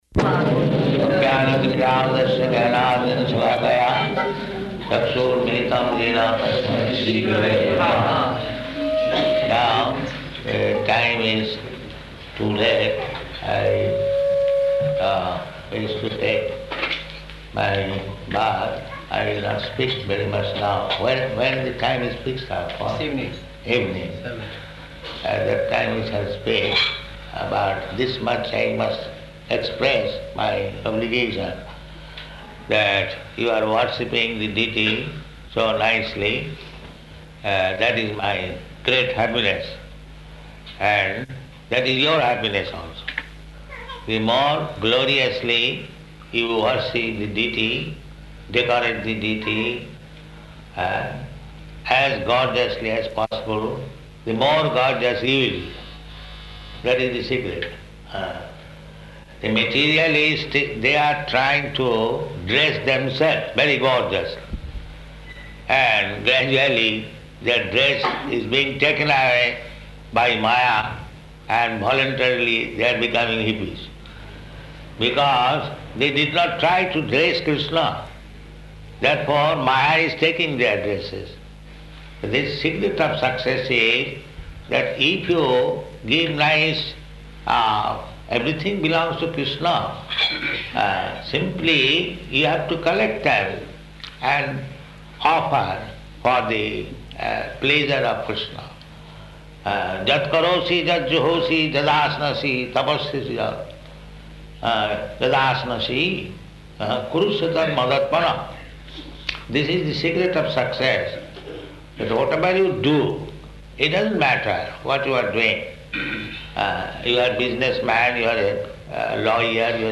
Arrival Lecture
Location: Melbourne